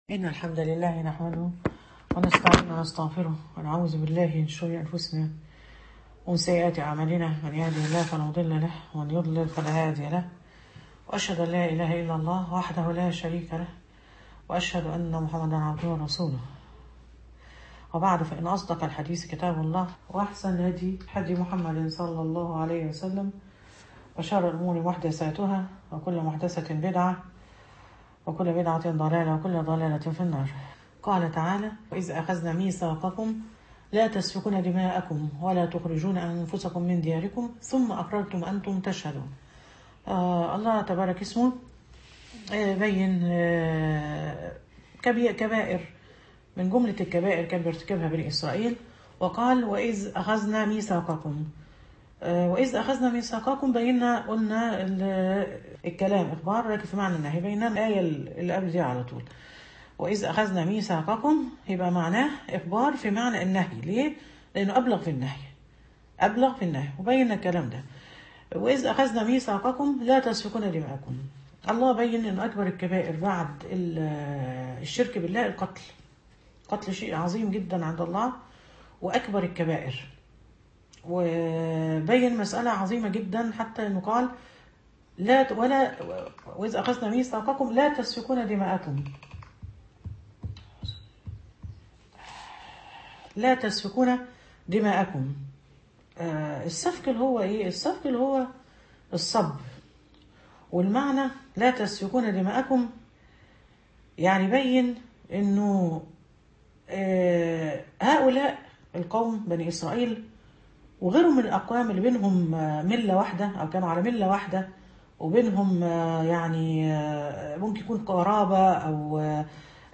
تدبر سورة البقرة المحاضرة السادسة عشر من آية “84: 90”